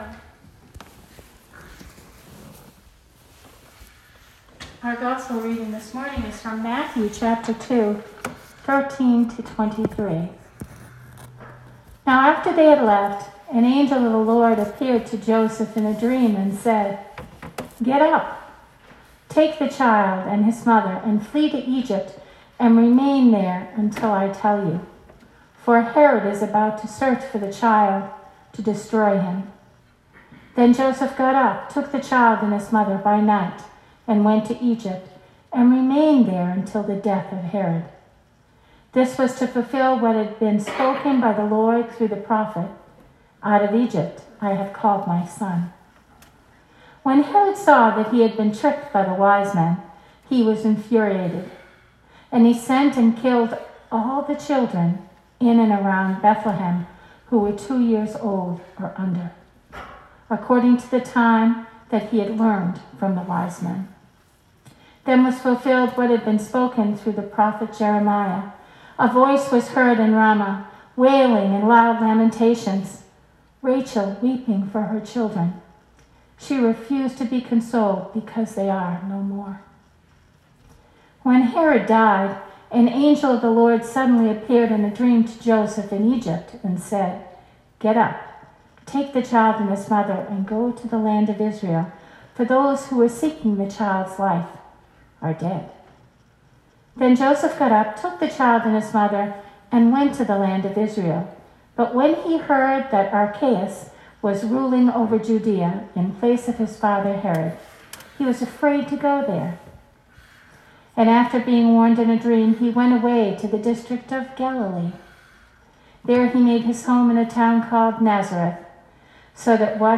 Sermon 2019-12-29